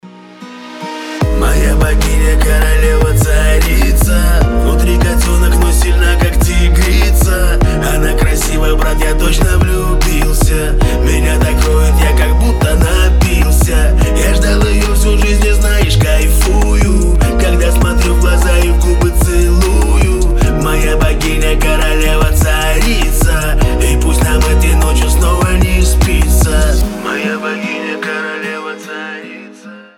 Гитара
Рэп